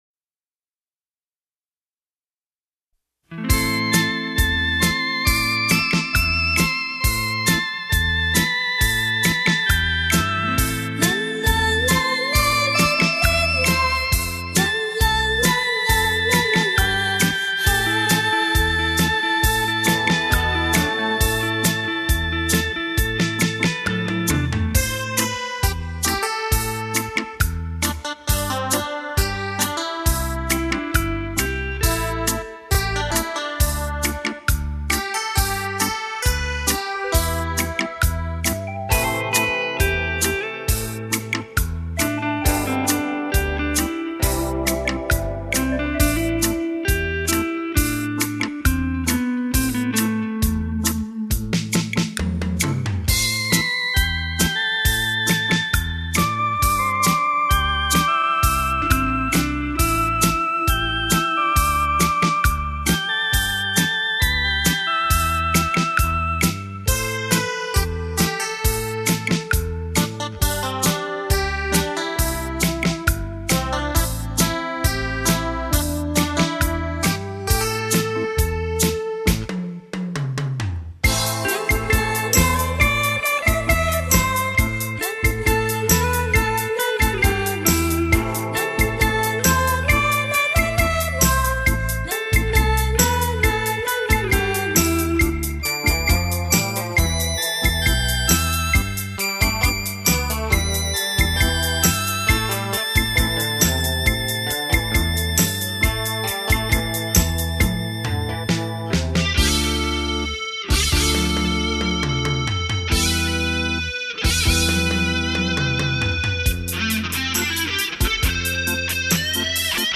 清新典雅的韵味、超凡脱俗的享受，